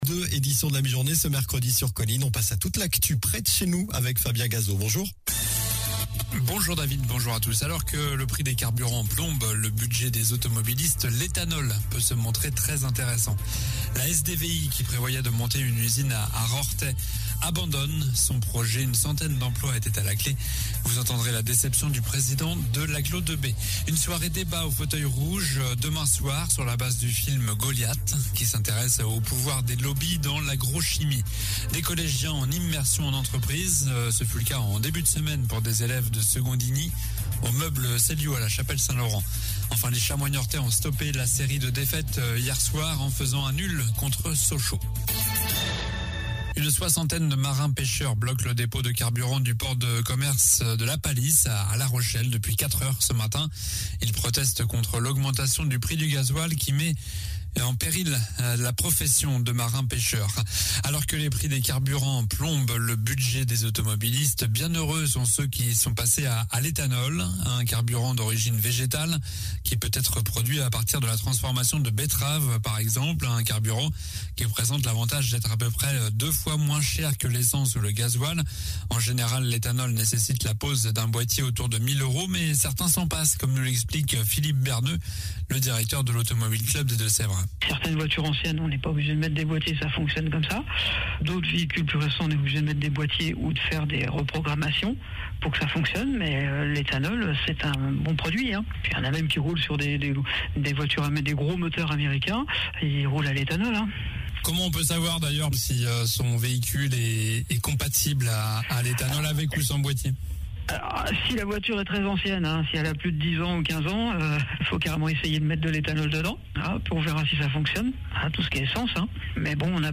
Journal du mercredi 16 mars (midi)